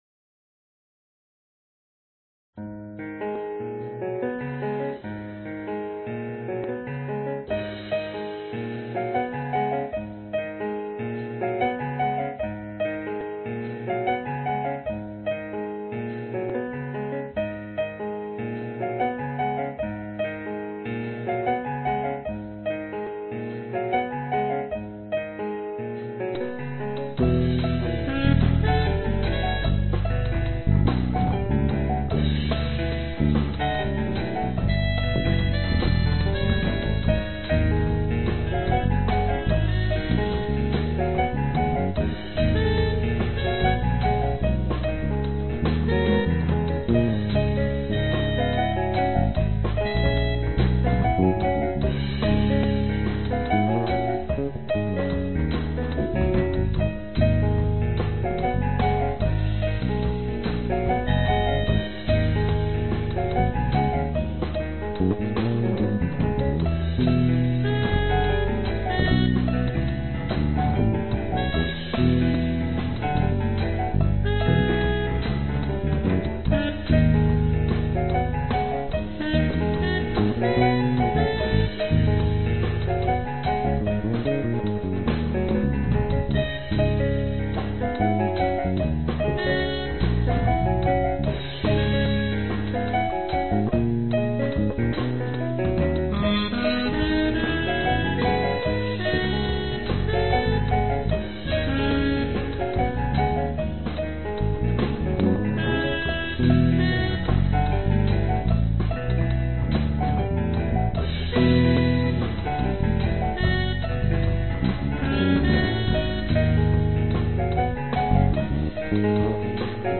piano bass induet downtempo jazz jazzy improvisation
声道立体声